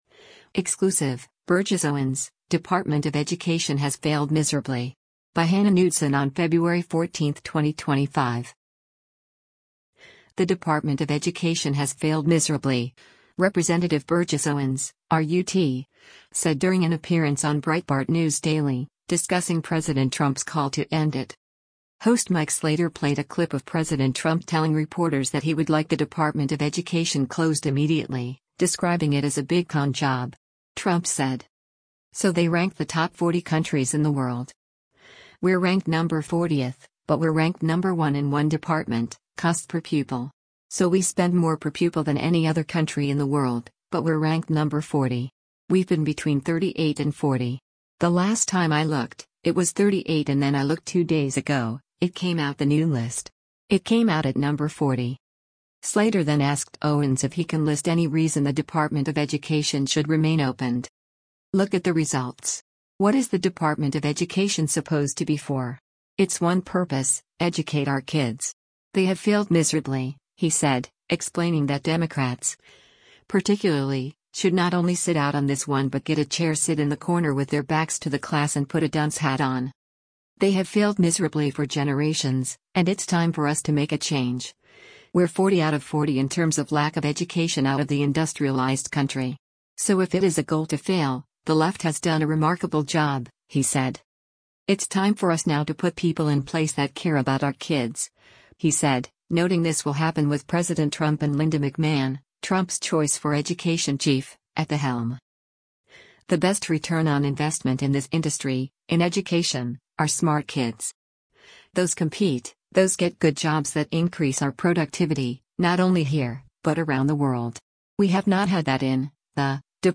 The Department of Education has “failed miserably,” Rep. Burgess Owens (R-UT) said during an appearance on Breitbart News Daily, discussing President Trump’s call to end it.